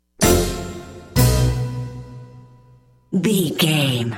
Aeolian/Minor
orchestra
percussion
silly
circus
goofy
comical
cheerful
perky
Light hearted
quirky